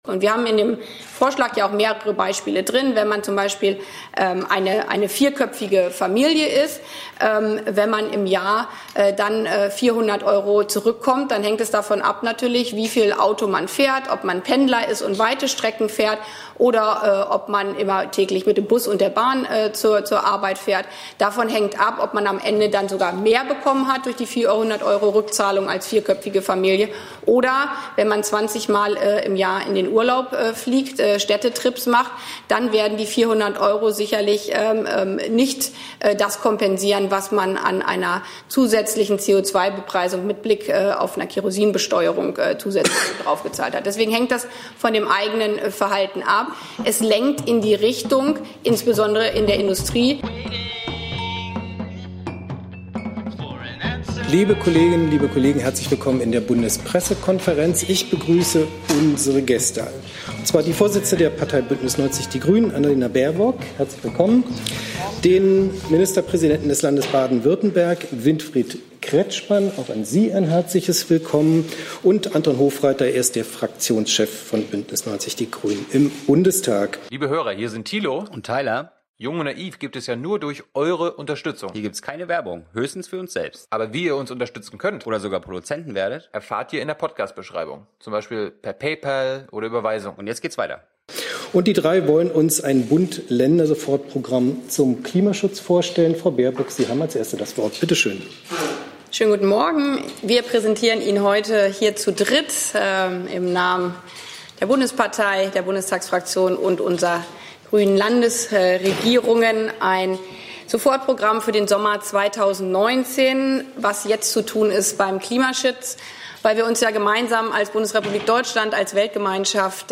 BPK - Grünes Sofortprogramm zum Klimaschutz - Annalena Baerbock, Toni Hofreiter, Winfried Kretschmann - 28.Juni 2019 ~ Neues aus der Bundespressekonferenz Podcast